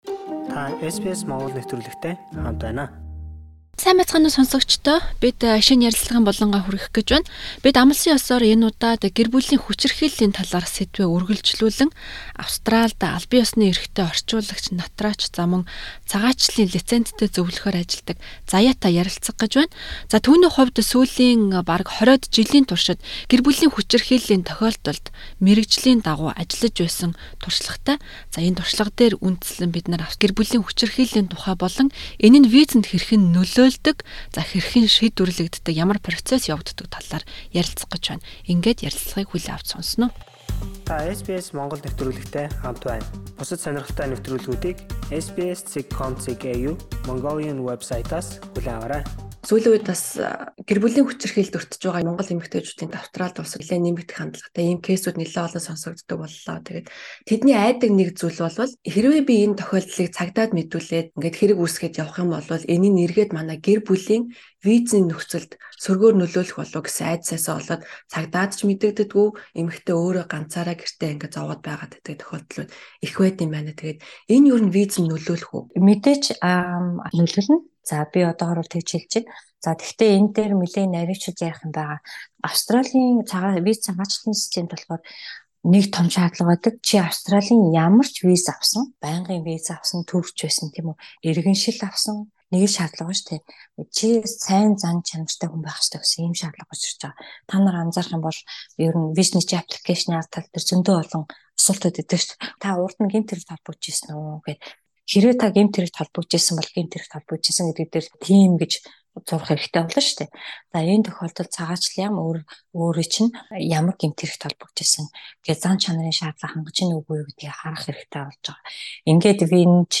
Гэхдээ хүчирхийлэлд өртсөн хүнийг хамгаалах хүчирхэг систем Австралид байдаг бөгөөд та хэрхэн эдгээрт хандах тухай нэвтрүүлэгийг эндээс сонсоорой.